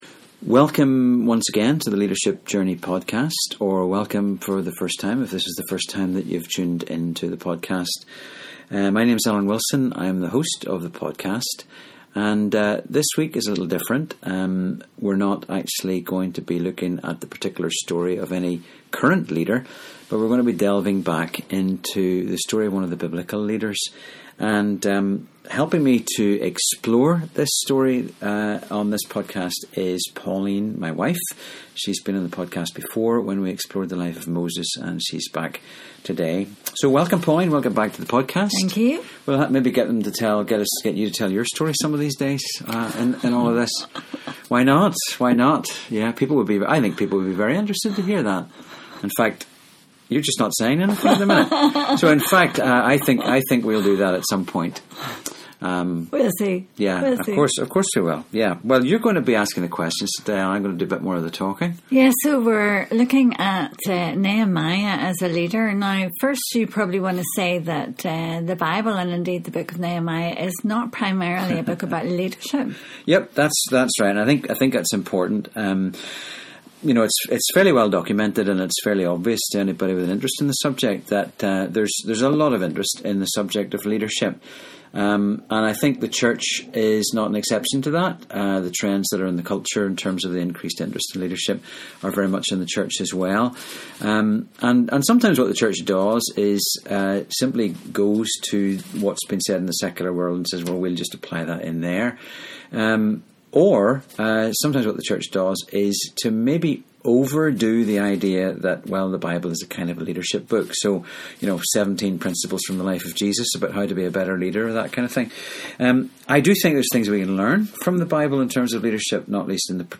This week’s podcast was recorded with an audience (and live-streamed) at New Horizon in Coleraine.